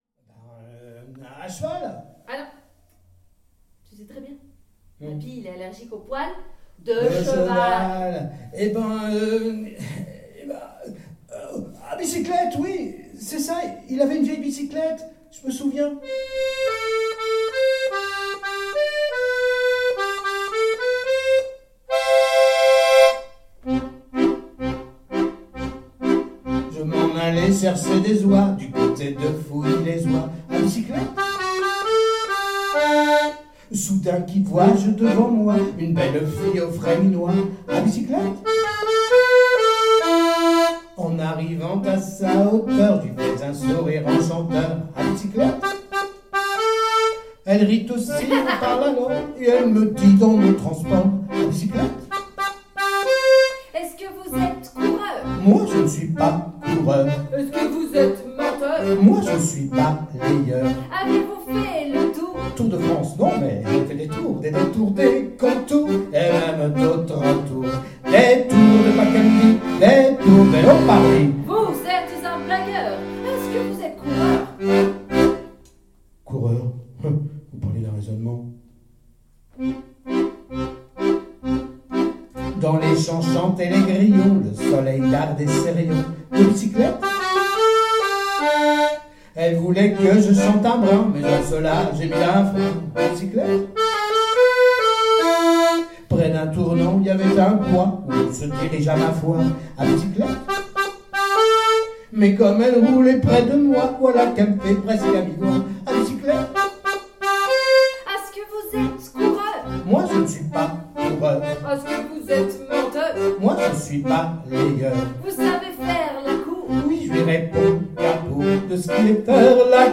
Un concert du genre cabaret qui ne manque pas de pétillant !